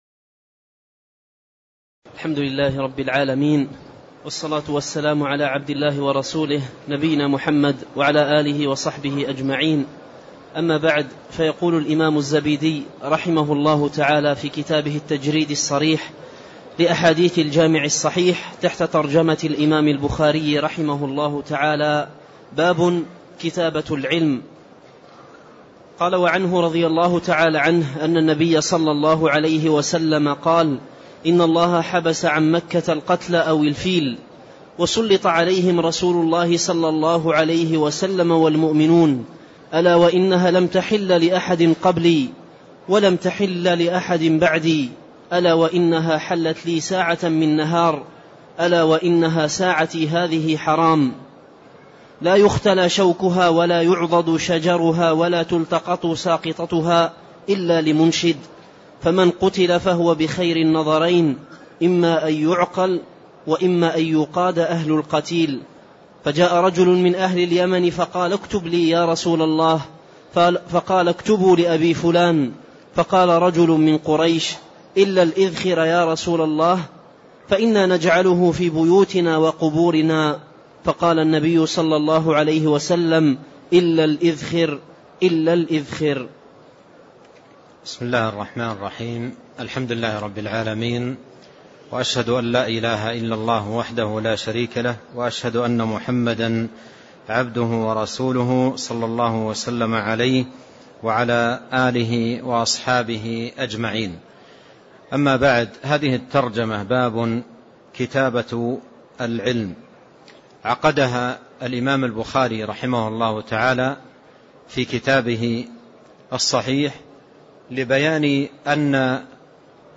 تاريخ النشر ١٦ جمادى الأولى ١٤٣٣ هـ المكان: المسجد النبوي الشيخ